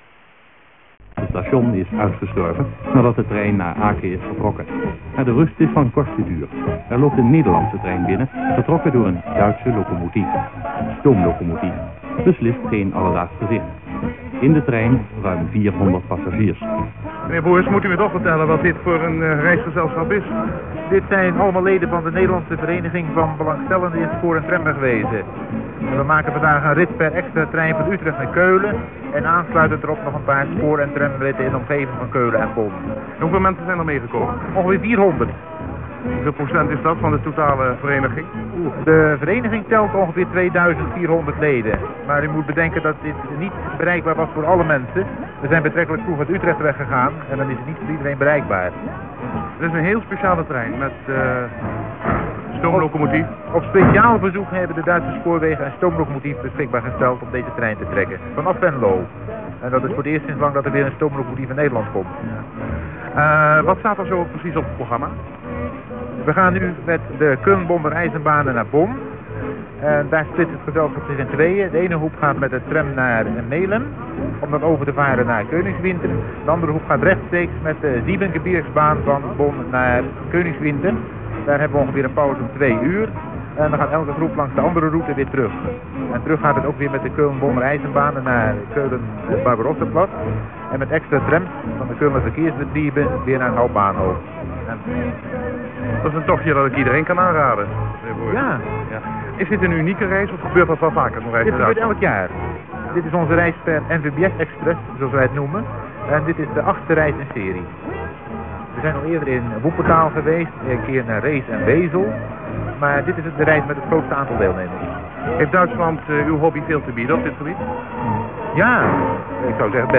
Dit interview heb ik in Hilversum opgenomen; het wordt enigszins gestoord door een andere zender.